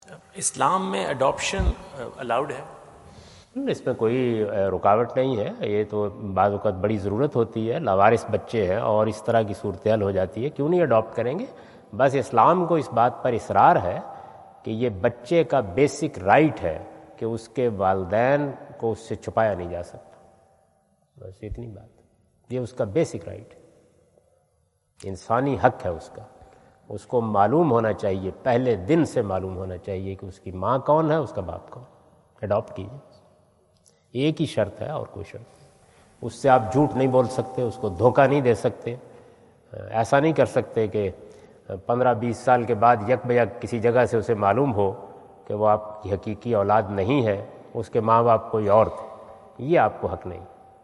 Javed Ahmad Ghamidi answer the question about "Adopting kids and Islam?" During his US visit at Wentz Concert Hall, Chicago on September 23,2017.
جاوید احمد غامدی اپنے دورہ امریکہ2017 کے دوران شکاگو میں "بچہ گود لینا اور اسلام؟" سے متعلق ایک سوال کا جواب دے رہے ہیں۔